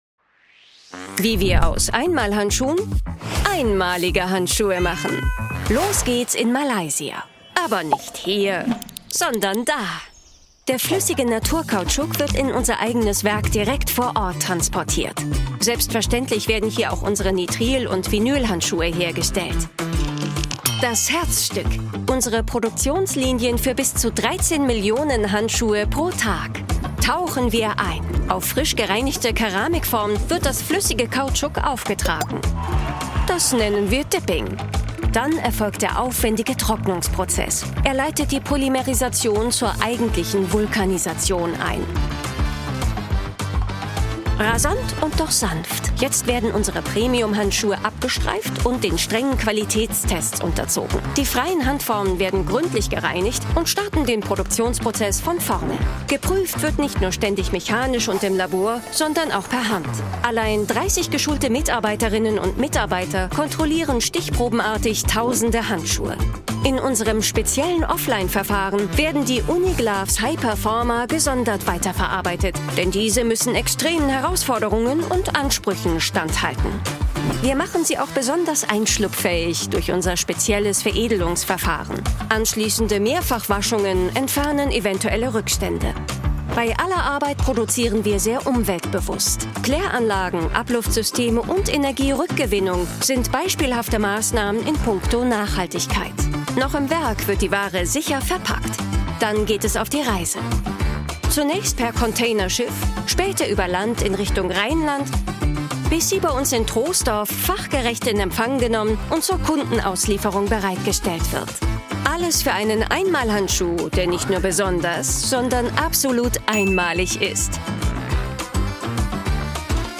Explainer Videos
My delivery is natural, engaging, and precise — ideal for brands that value credibility, emotion, and clarity.
🎧 Voice style: Warm · Modern · Confident · Engaging · Trustworthy · Natural